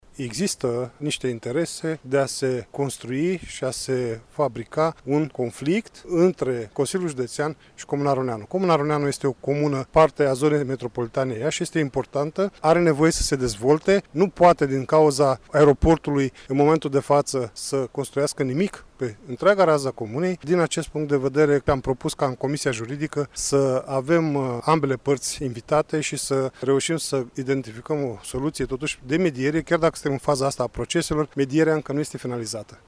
La rândul său, preşedintele grupului liberal din Consiliul Judeţean Iaşi, Romeo Vatră, a declarat că este necesară o mediere în disputa dintre aeroport şi comuna Aroneanu: